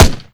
sol_reklam_link sag_reklam_link Warrock Oyun Dosyalar� Ana Sayfa > Sound > Weapons > UZI Dosya Ad� Boyutu Son D�zenleme ..
WR_Fire.WAV